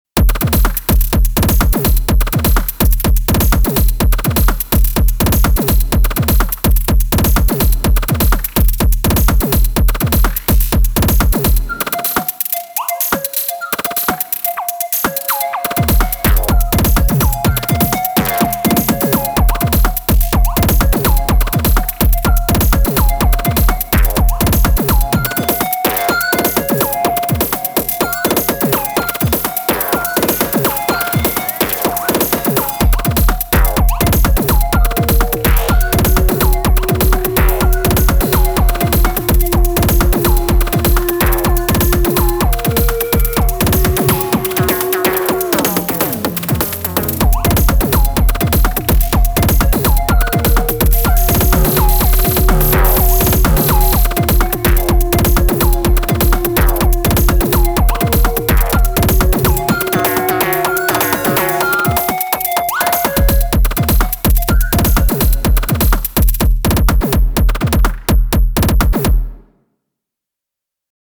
5-6 tracks per pattern, with really 4 patterns and a couple variations of 2 (for 6 patterns).